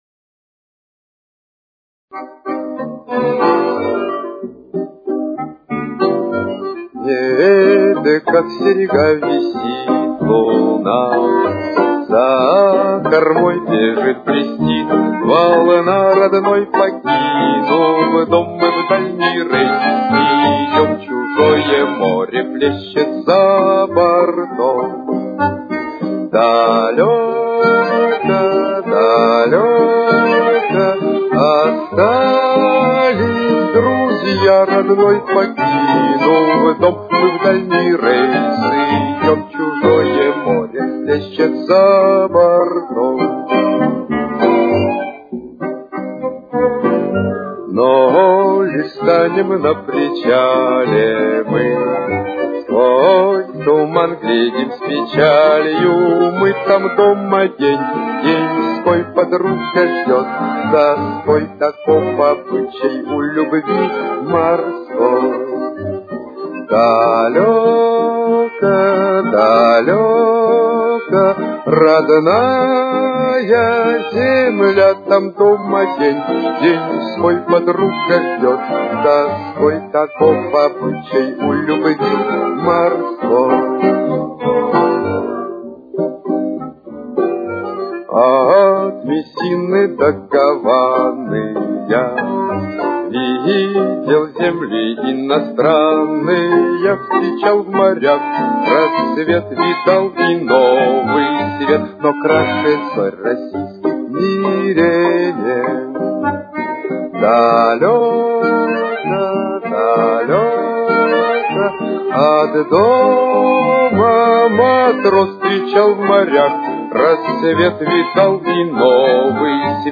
Темп: 99.